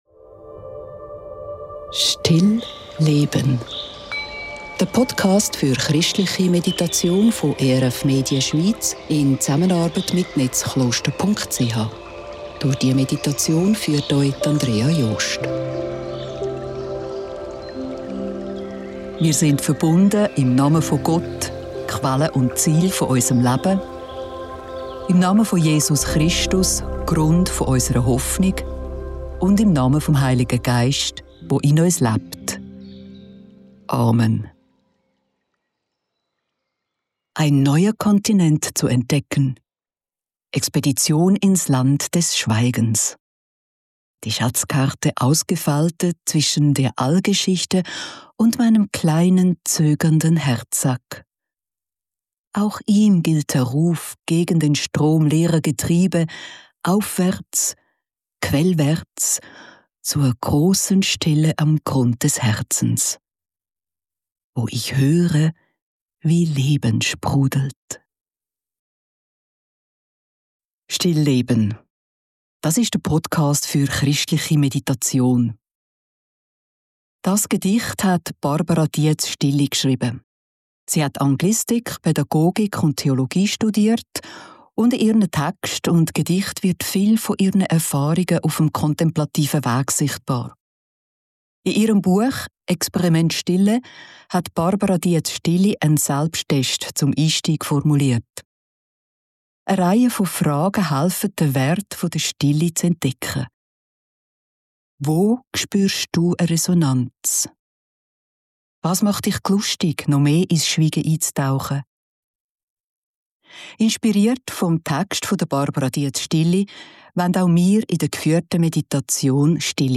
Inspiriert von den Texten von Barbara Dietz-Stilli möchten wir in der geführten Meditation «Stille schnuppern».